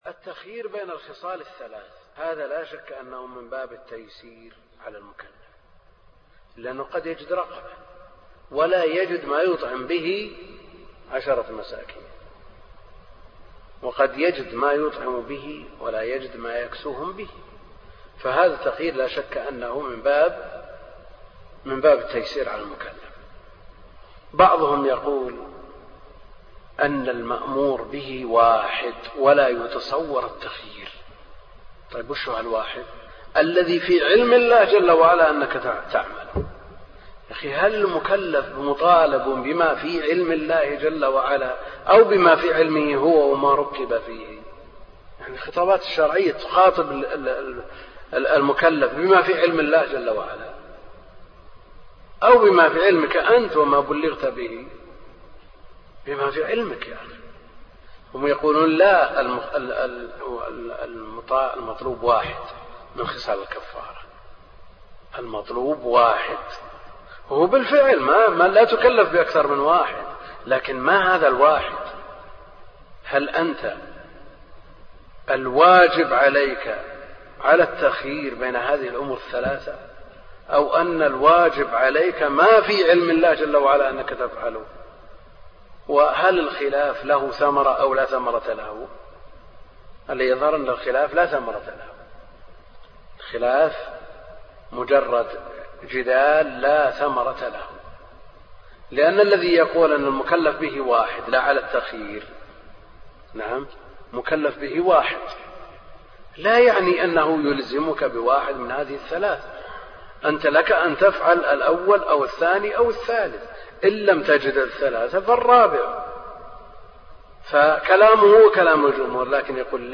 أرشيف الإسلام - ~ أرشيف صوتي لدروس وخطب ومحاضرات الشيخ عبد الكريم الخضير